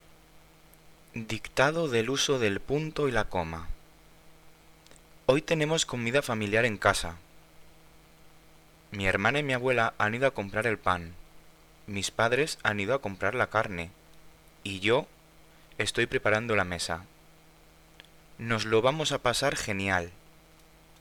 Dictado
Presta atención a las pausas y utiliza los signos de puntuación que corresponden de forma correcta:
Dictado.mp3